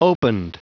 Prononciation du mot opened en anglais (fichier audio)
Prononciation du mot : opened